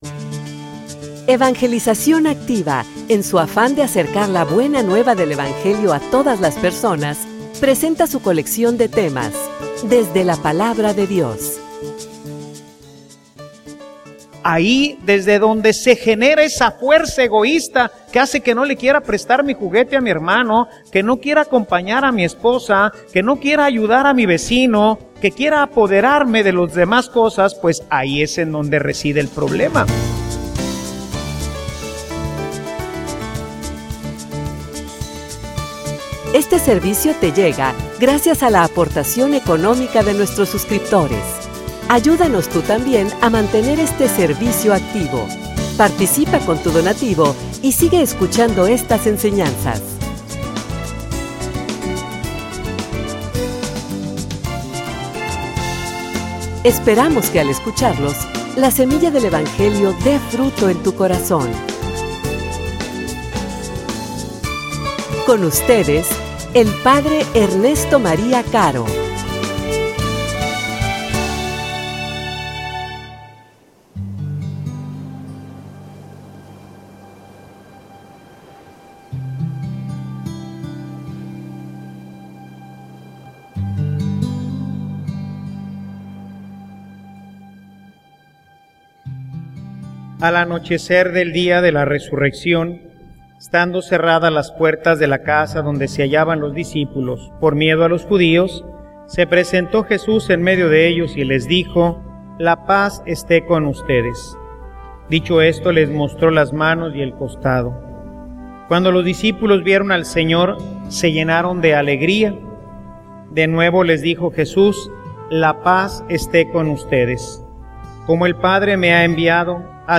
homilia_Una_vida_sin_egoismo.mp3